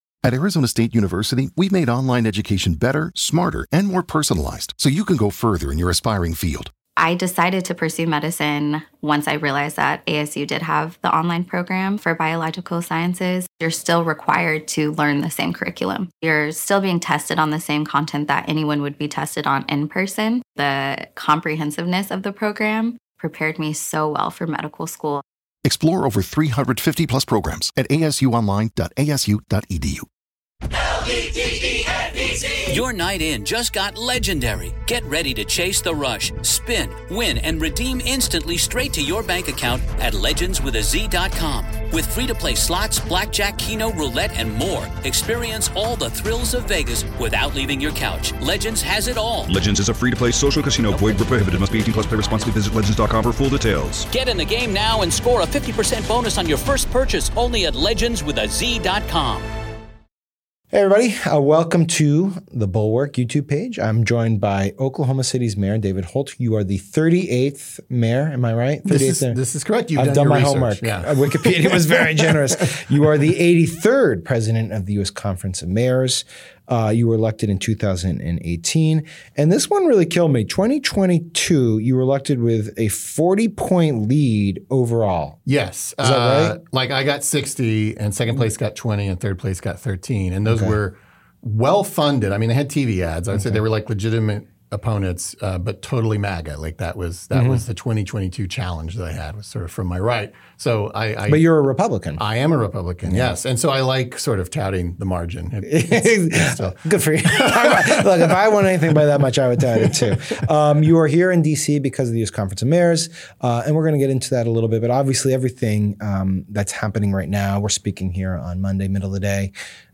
Sam Stein speaks with Mayor David Holt about America’s struggle with political violence, from the Oklahoma City bombing to today’s climate after Charlie Kirk’s assassination, and how local leaders are finding common ground on housing, crime, and inclusion.